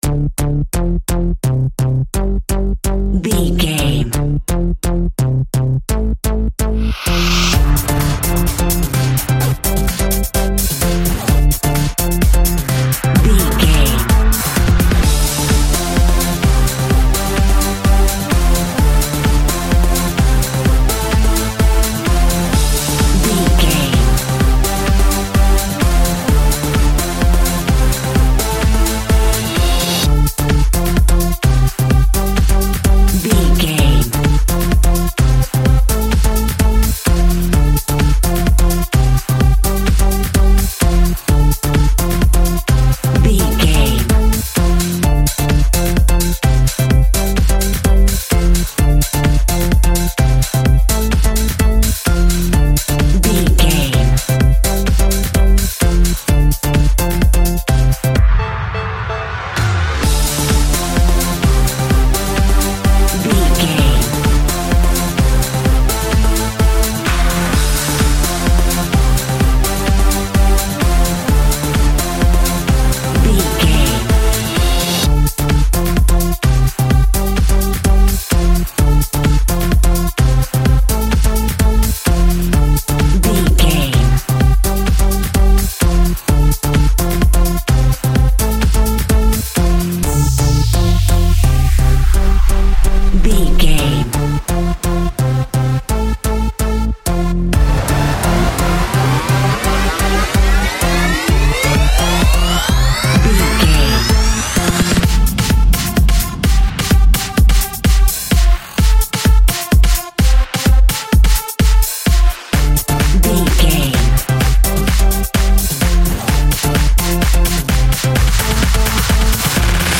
Club House Music Theme.
Epic / Action
Fast paced
Aeolian/Minor
dark
futuristic
groovy
aggressive
piano
electric piano
synthesiser
drum machine
electro dance
electronic
instrumentals
synth leads
synth bass
upbeat